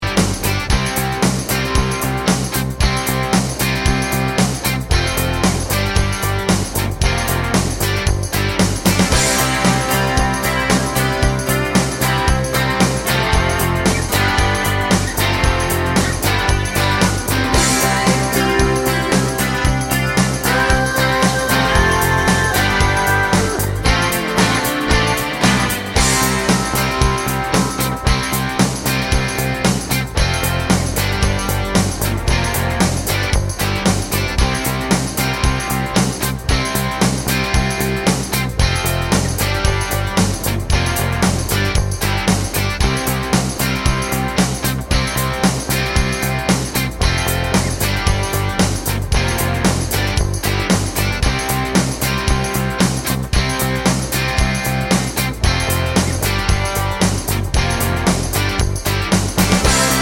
no Backing Vocals Rock 3:42 Buy £1.50